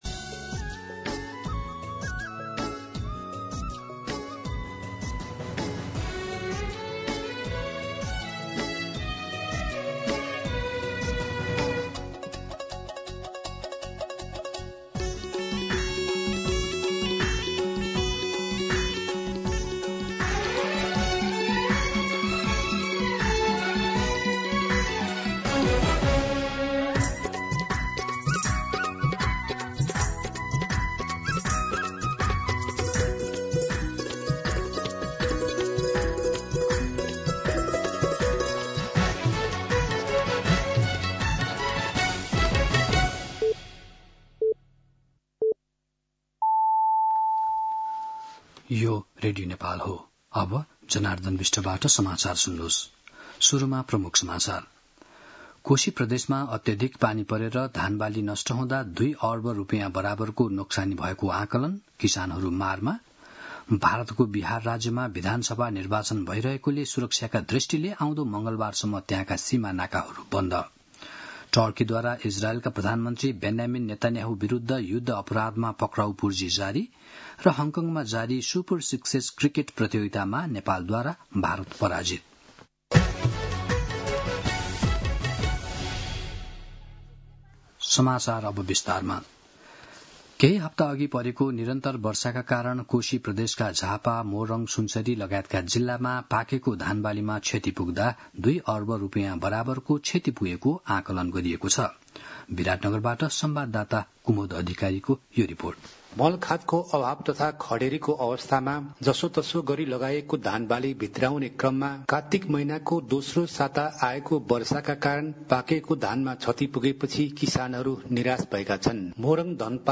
दिउँसो ३ बजेको नेपाली समाचार : २२ कार्तिक , २०८२
3-pm-Nepali-News-.mp3